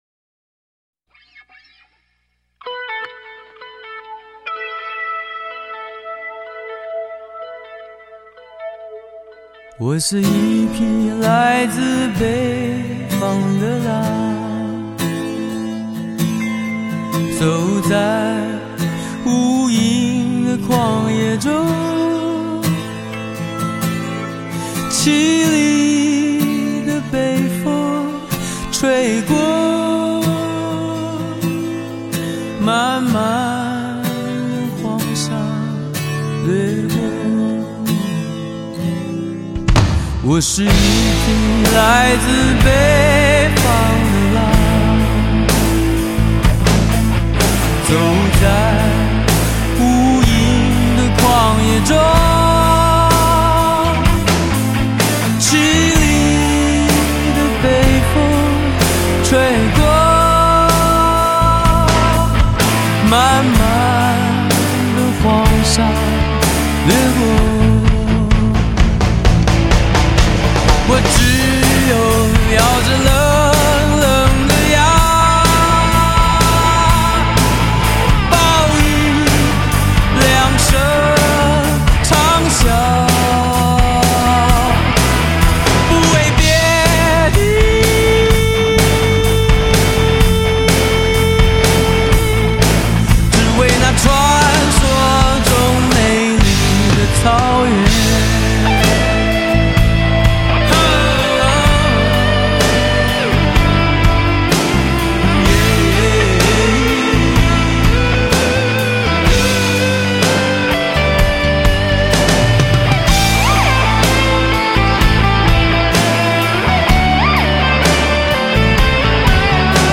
XRCD版在动态上胜过首版，